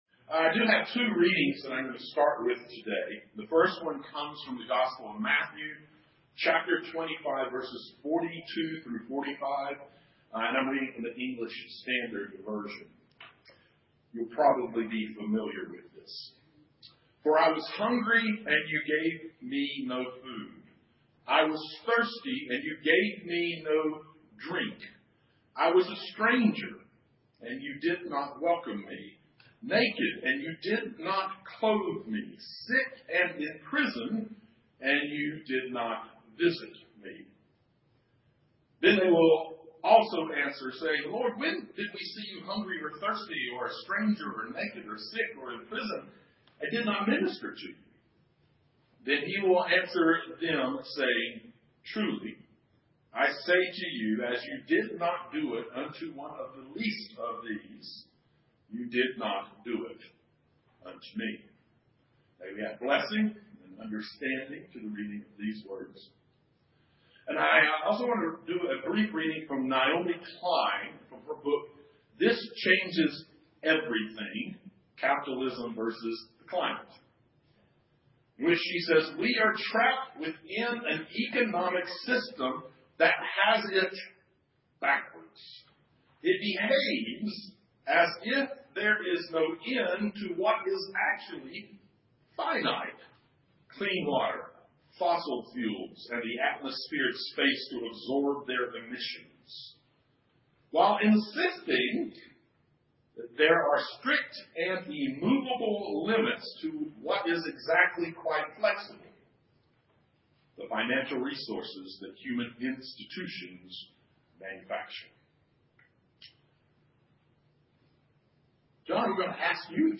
Guest Percussionist